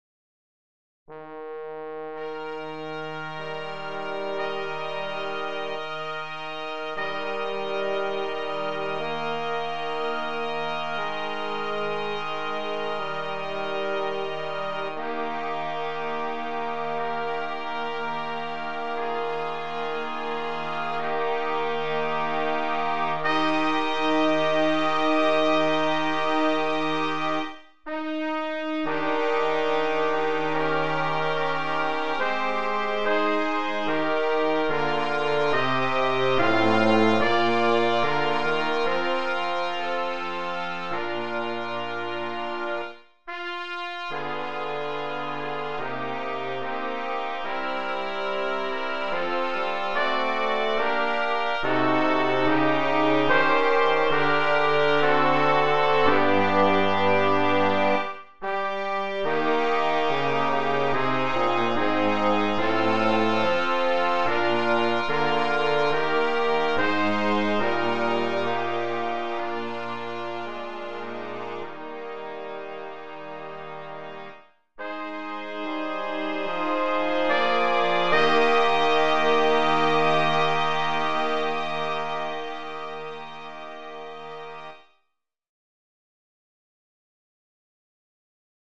Voicing: Brass Quartet